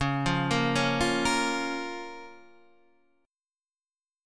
C#6 chord